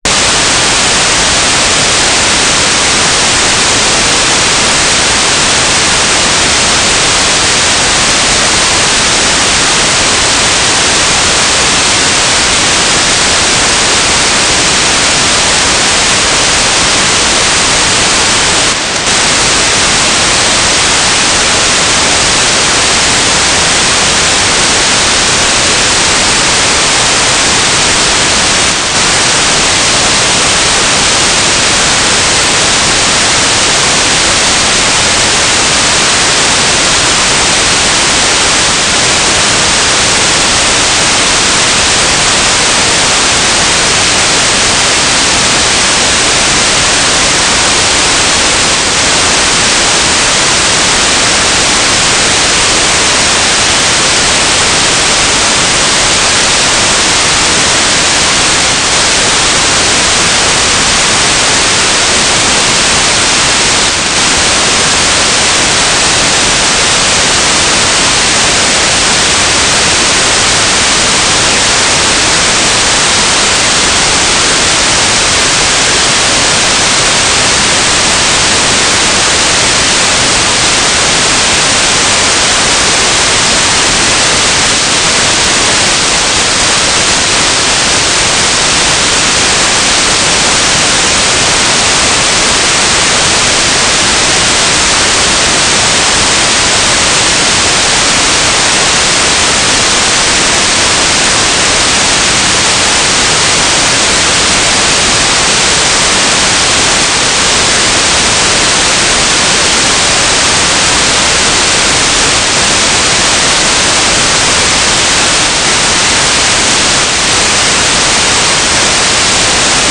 "transmitter_description": "Mode U - GFSK9k6 - AX.25 G3RUH",